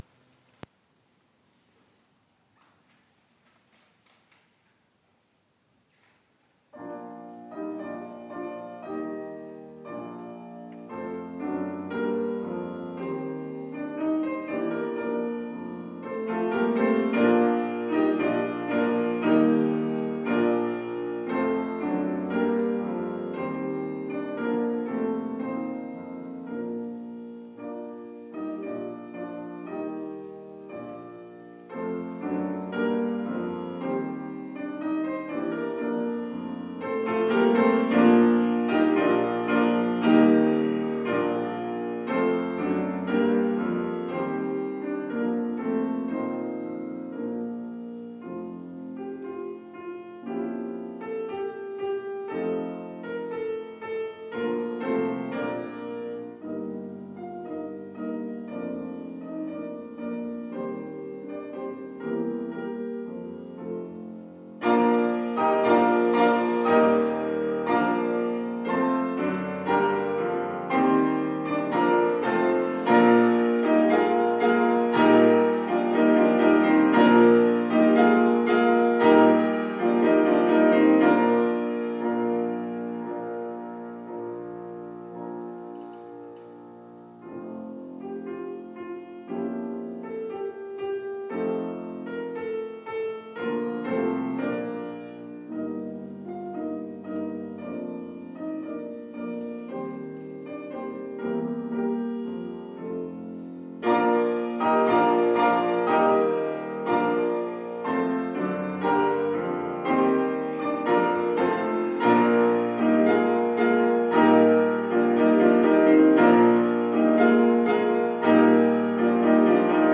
Klavierduett 2